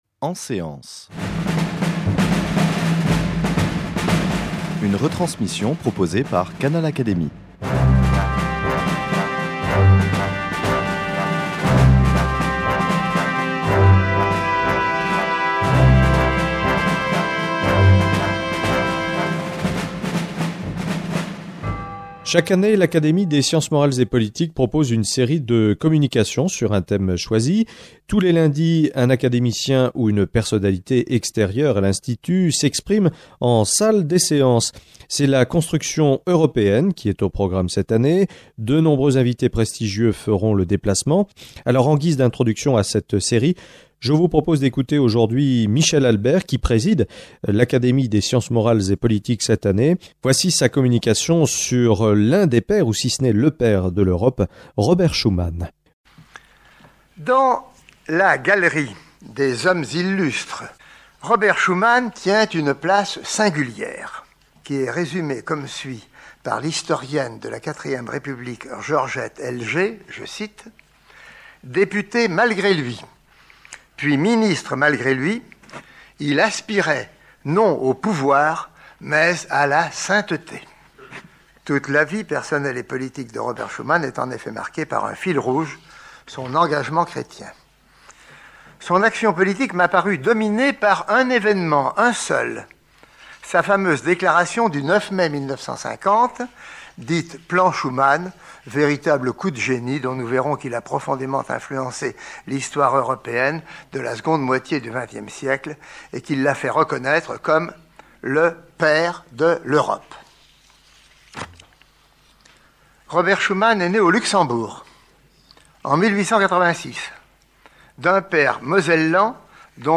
Michel Albert, Secrétaire perpétuel de l’Académie des sciences morales et politiques, a prononcé une communication en séance publique devant l’Académie en 2003, dans laquelle il présente à la fois la personnalité de Robert Schuman et son "audace inouïe", son rôle éminent dans la naissance de la construction européenne, la particularité du fameux "plan Schuman", le développement de la CECA.